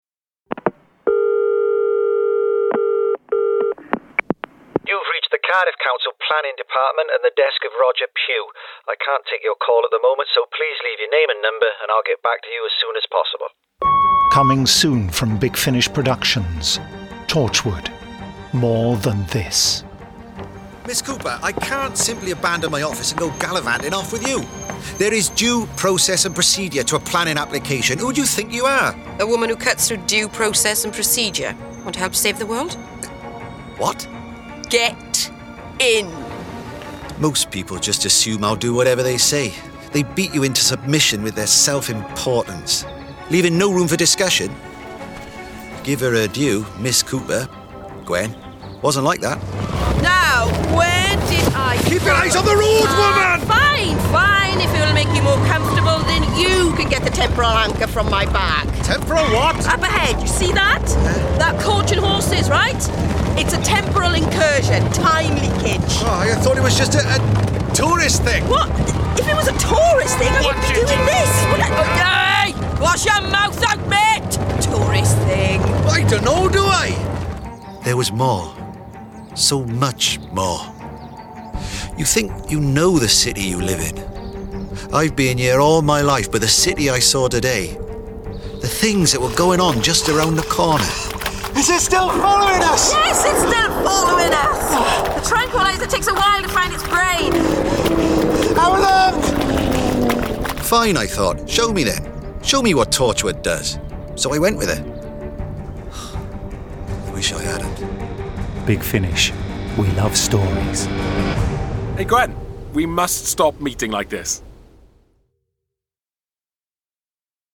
Award-winning, full-cast original audio dramas from the worlds of Doctor Who, Torchwood, Blake's 7, Class, Dark Shadows, The Avengers, Survivors, The Omega Factor, Star Cops, Sherlock Holmes, Dorian Gray, Pathfinder Legends, The Prisoner, Adam Adamant Lives, Space 1999, Timeslip, Terrahawks, Space Precinct, Thunderbirds, Stingray, Robin Hood, Dark Season, UFO, Stargate
Torchwood - Monthly Range 06. Torchwood: More Than This Available February 2016 Written by Guy Adams Starring Eve Myles This release contains adult material and may not be suitable for younger listeners. From US $10.04 Download US $10.04 Buy Save money with a bundle Login to wishlist 45 Listeners recommend this Share Tweet Listen to the trailer Download the trailer